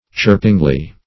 chirpingly - definition of chirpingly - synonyms, pronunciation, spelling from Free Dictionary Search Result for " chirpingly" : The Collaborative International Dictionary of English v.0.48: Chirpingly \Chirp"ing*ly\, adv.